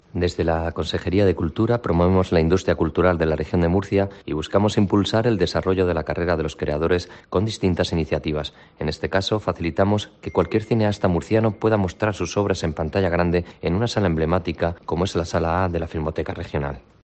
Manuel Cebrián, director del ICA